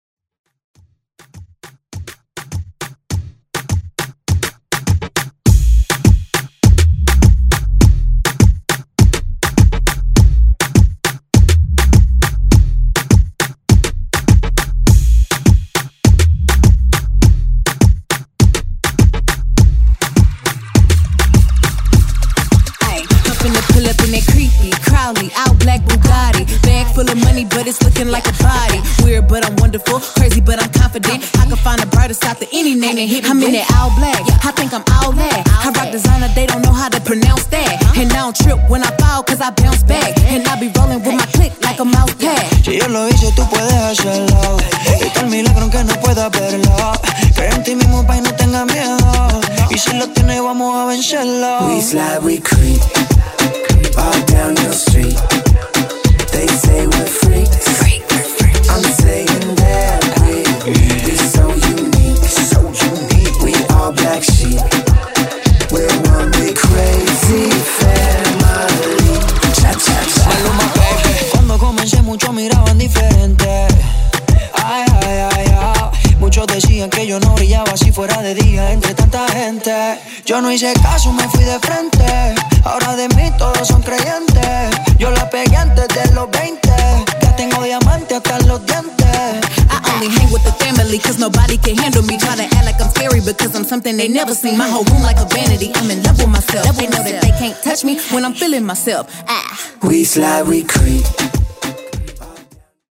BPM: 102 Time